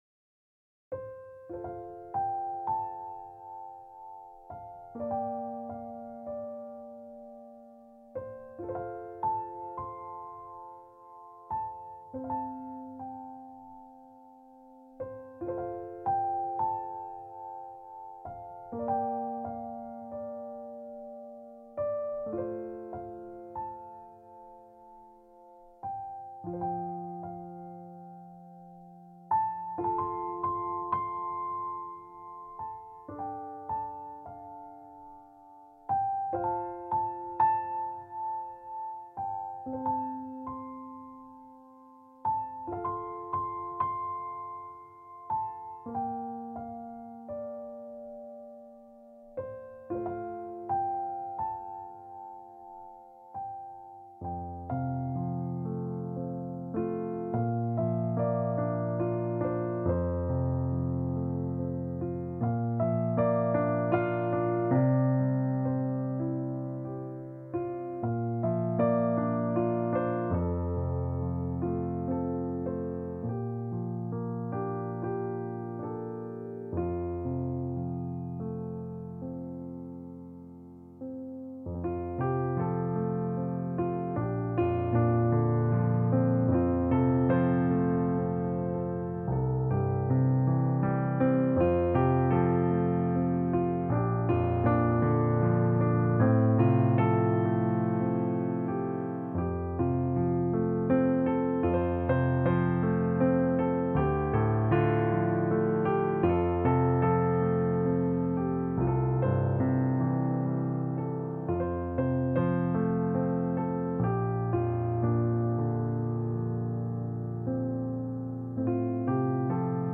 Voicing/Instrumentation: Piano Solo